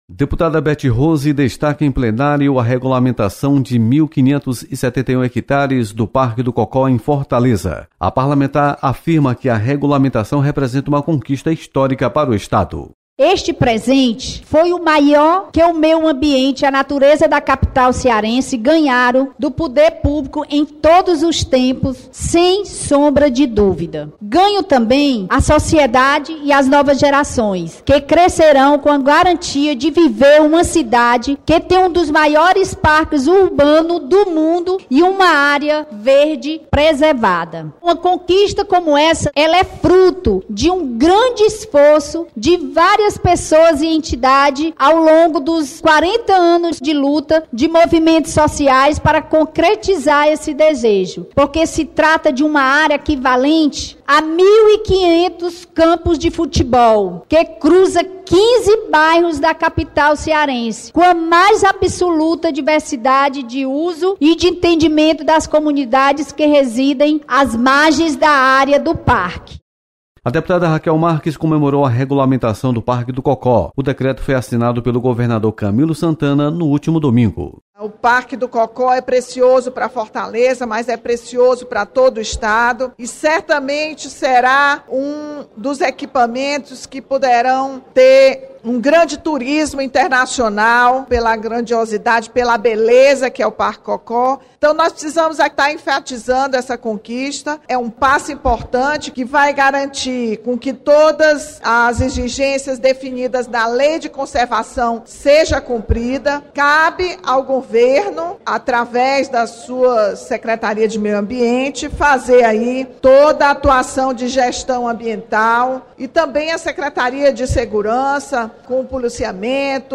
Parlamentares comemoram regulamentação do Parque do Cocó. Repórter